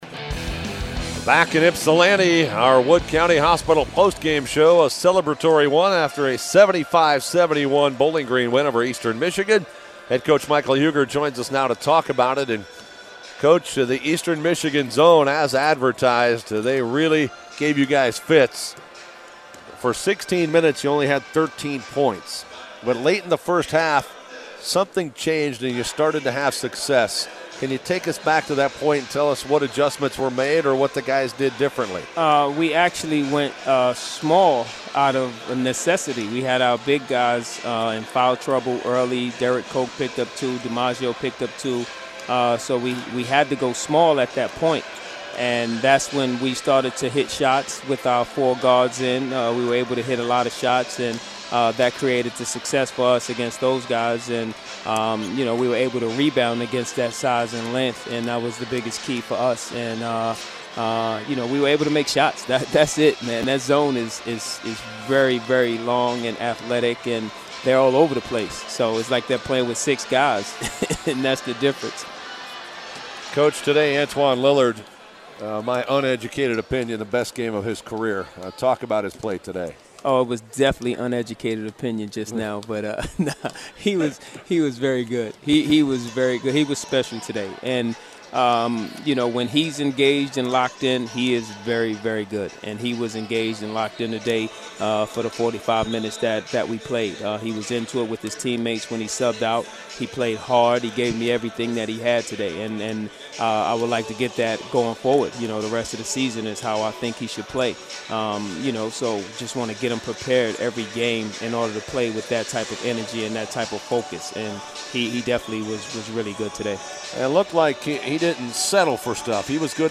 Post-Game Audio: